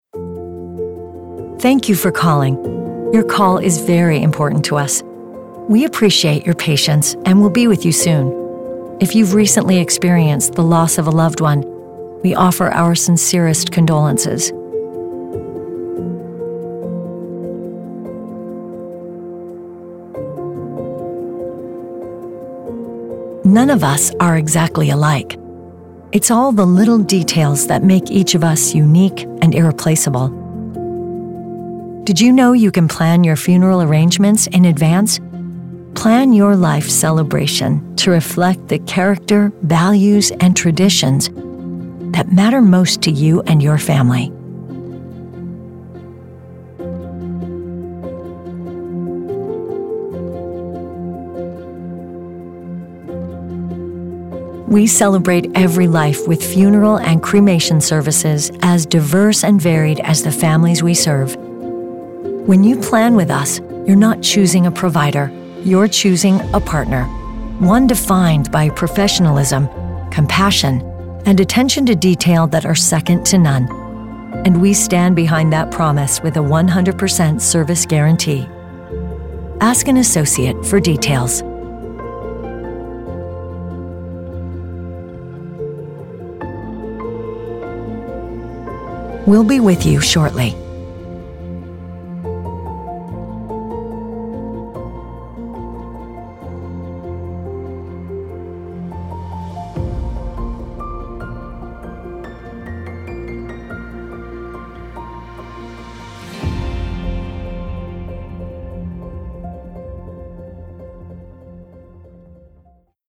Dignity Memorial On-Hold Message
on-hold-message-funeral